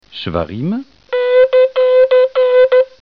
Le Choffar
La "Terouah" ressemble à des sanglots, et deux variantes en sont retenues: des sons brefs à moyens, presque des râles, dits "
Chevarim", au nombre de trois, et des sons courts, ponctuels, en cascade, dits "
Les Chevarim comportent trois, et trois seulement sons courts, dont chacun a la durée minimum de trois sons brefs de la Terouah.
chevarim.mp3